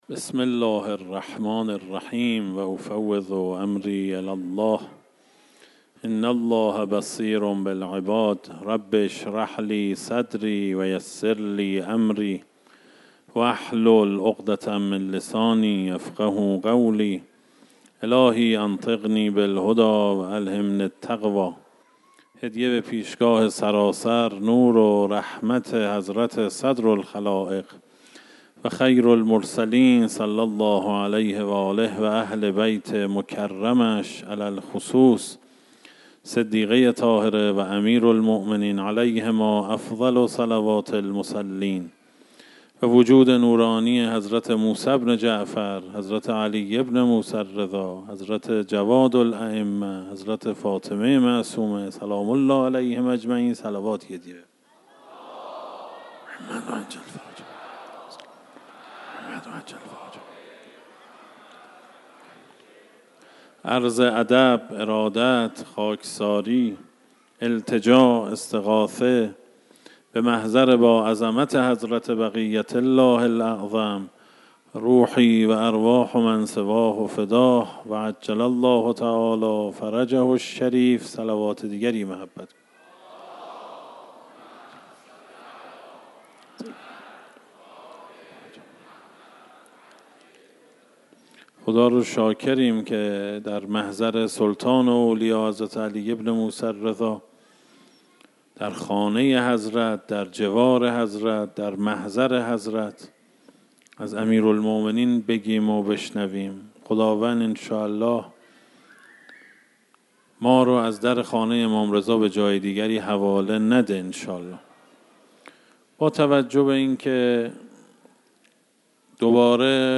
اشتراک گذاری دسته: امیرالمومنین علیه السلام , سخنرانی ها , منبر فضائل علوی در آستان رضوی قبلی قبلی منبر فضائل علوی در آستان رضوی؛ جلسه چهارم